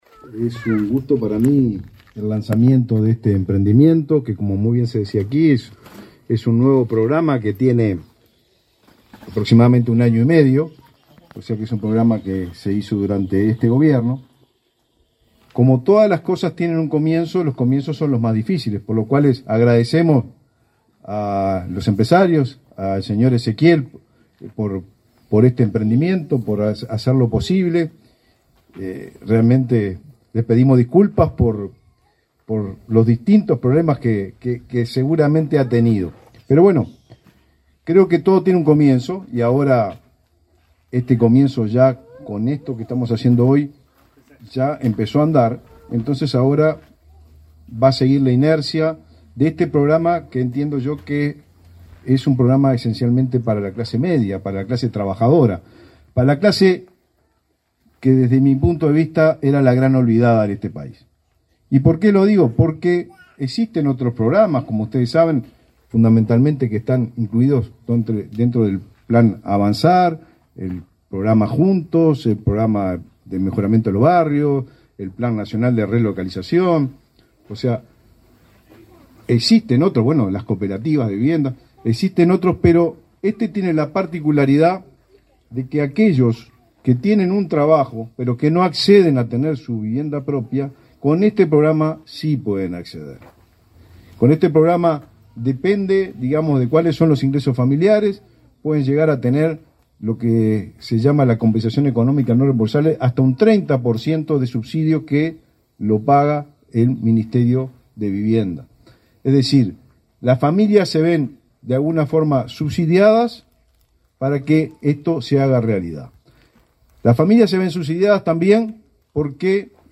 Palabras del ministro del MVOT, Raúl Lozano
El titular del Ministerio de Vivienda y Ordenamiento Territorial (MVOT), Raúl Lozano, participó en la inauguración de las primeras cuatro viviendas